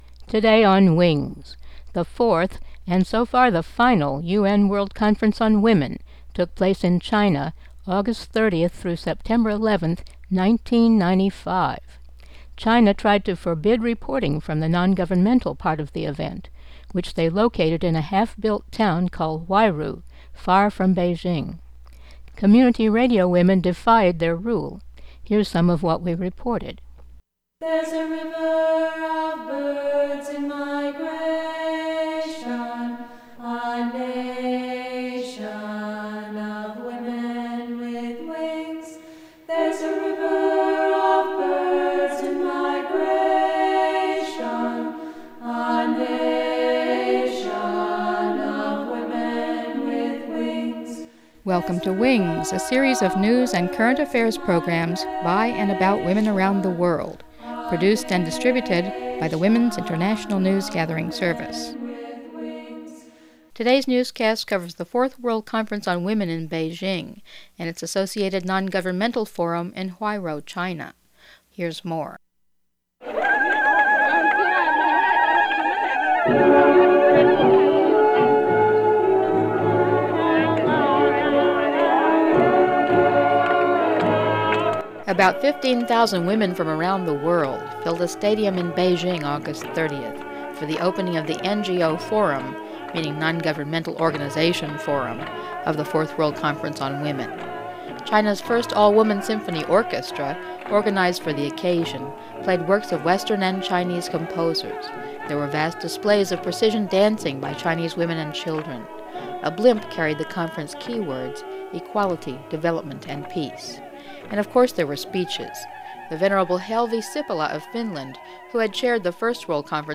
Singers from Western Sahara.